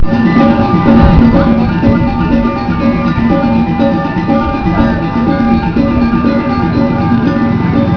Starting the procession to the cremation temple
Klung Kung, Bali, Indonesia